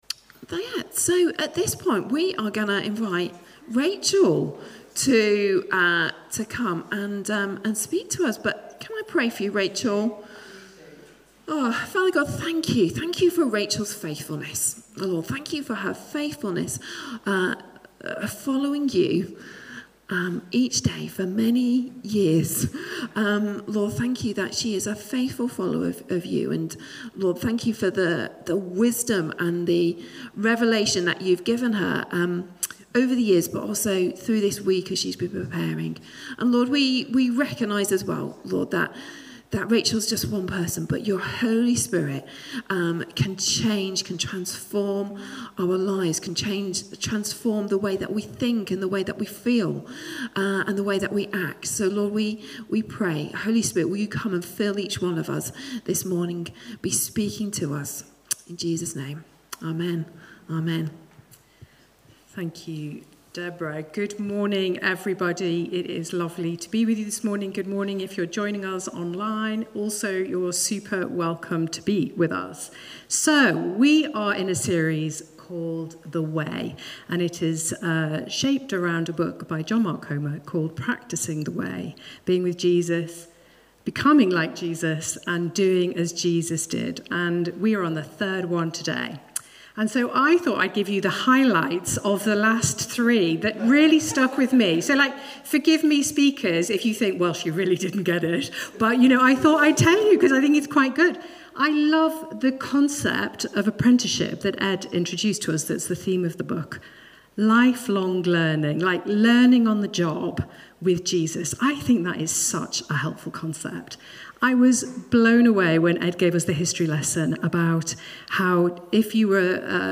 Weekly talks from Highgrove Church, Sea Mills, Bristol.